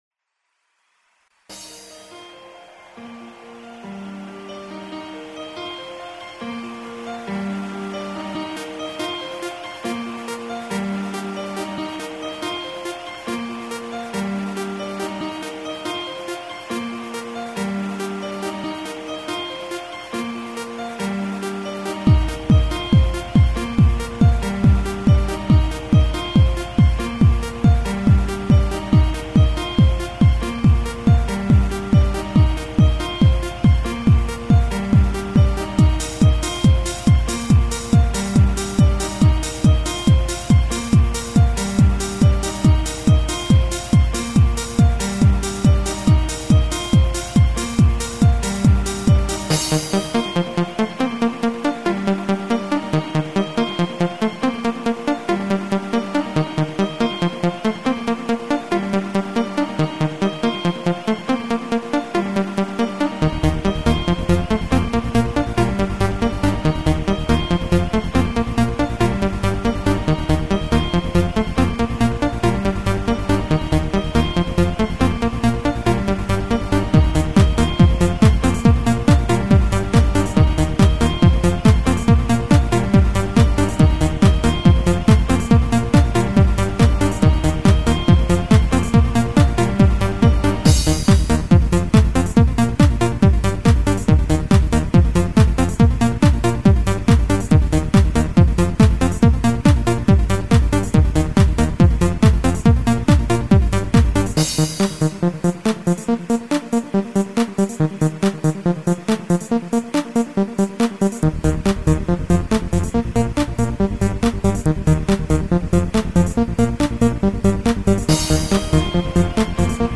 • Quality: 44kHz, Stereo